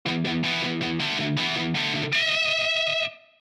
Vir2 Instruments社のギター音源「Electri6ity」でユニゾンチョーキングを打ち込む方法についてです。
２本の弦を同時に弾いて、低いほうの弦をチョーキングして、高い方の弦と同じ音程にするギターのテクニックです。
ただ、キュイーンと伸ばして弾くよりも、ビブラートをかけるとさらにそれっぽいと思います。
3弦の音のピッチを上げたり下げたりを交互にすばやくおこなってます。
ビブラート
logic-unison-bends-vibrato.mp3